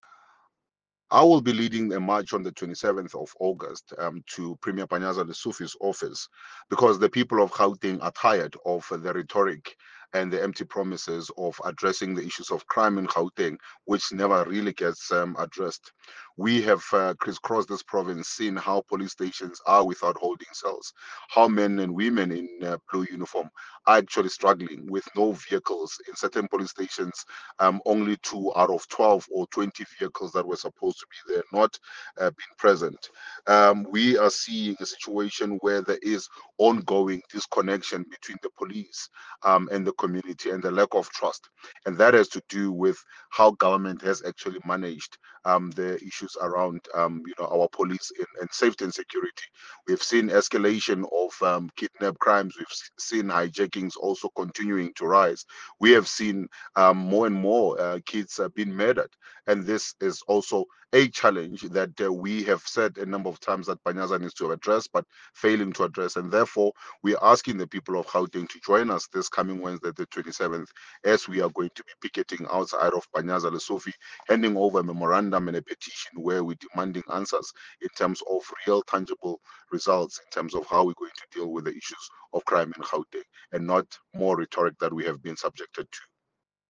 soundbite by Solly Msimanga MPL.